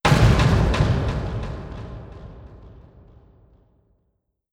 Jumpscare_02.wav